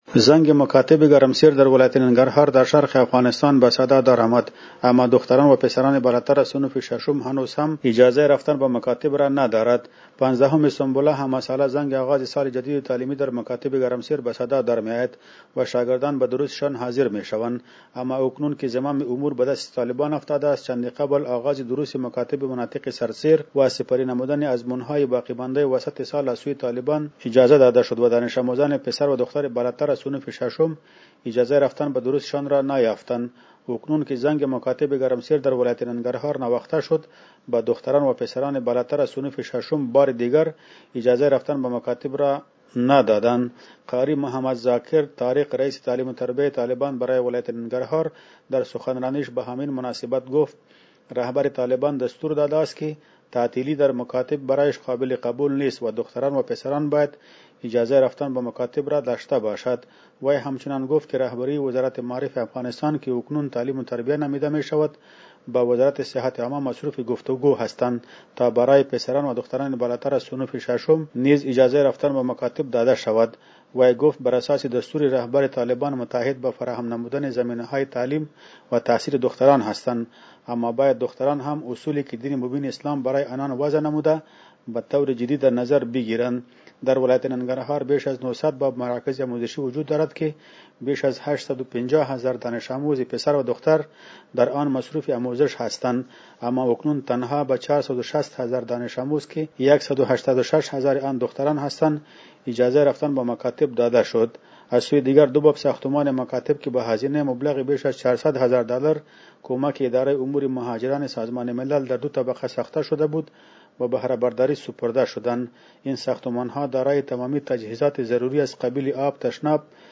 به گزارش رادیو دری، اکنون که زنگ مکاتب گرمسیر در ولایت ننگرهار نواخته شد به دختران وپسران بالاتر از صنوف ششم باردیگر اجازه رفتن به مکاتب را نیافتند.